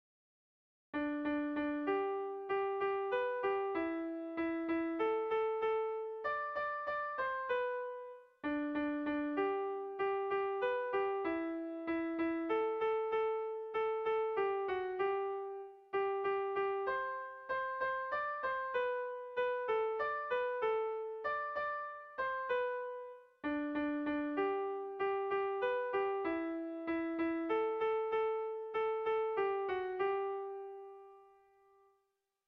Zortziko handia (hg) / Lau puntuko handia (ip)
A1A2BA2